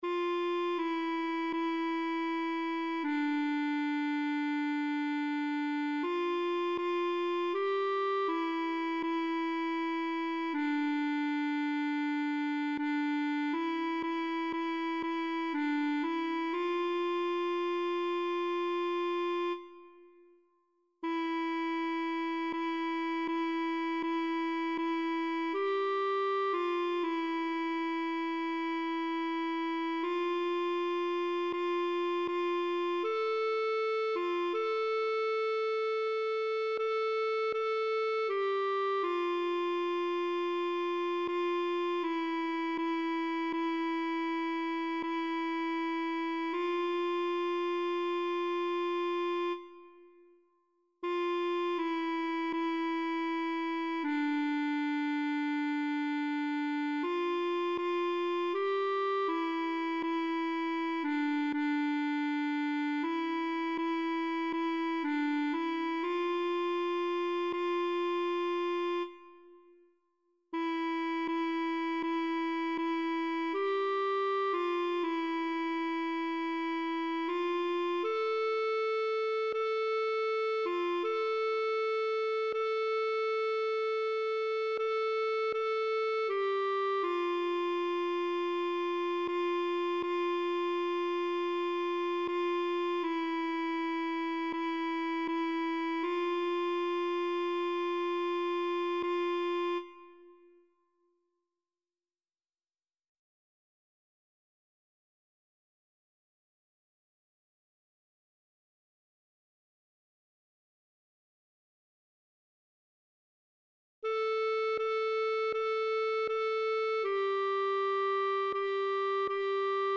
Voci (mp3): sopran,